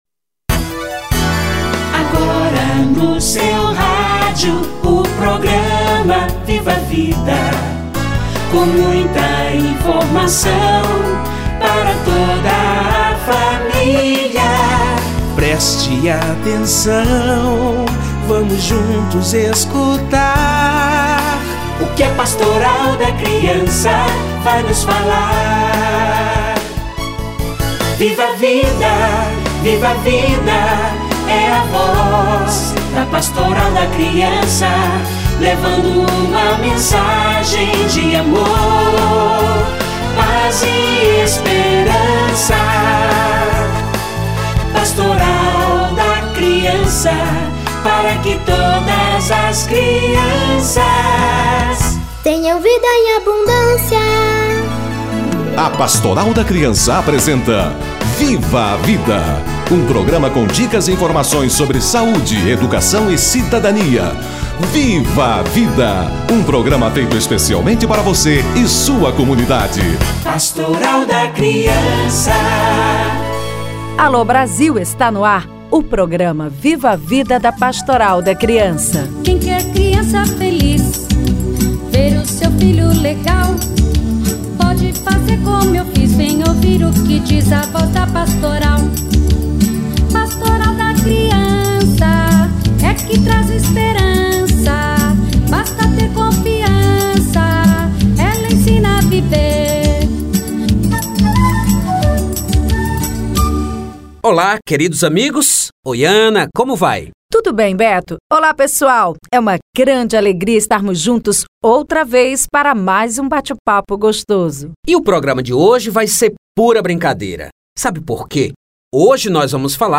Importância do brincar - Entrevista